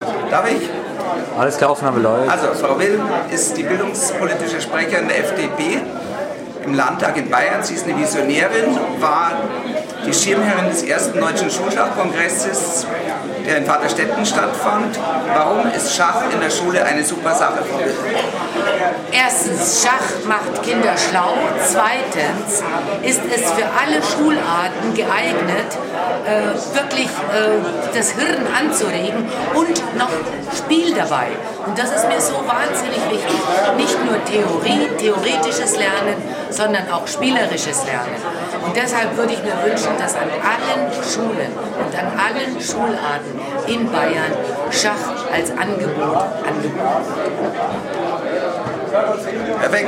Auch Renate Will, Bildungspolitische Sprecherin der FDP im Landtag über Schulschach:
BildungspolitscheSprecherinRenateWill.mp3